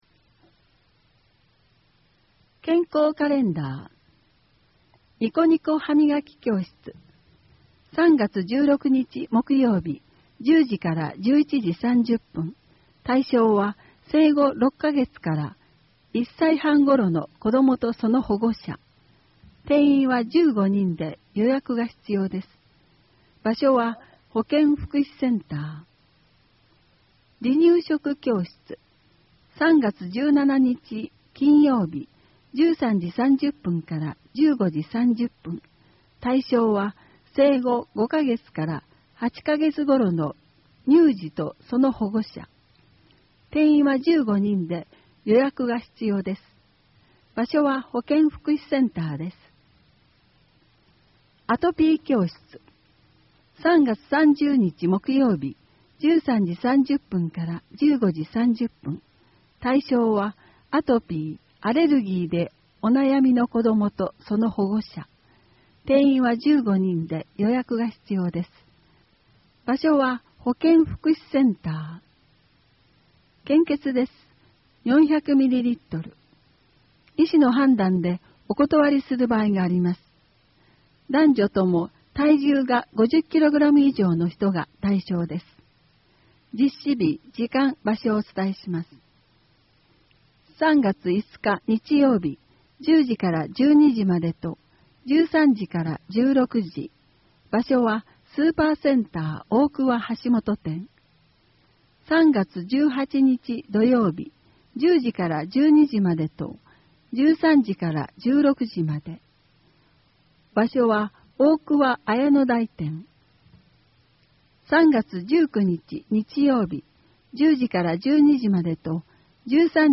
WEB版　声の広報 2017年3月号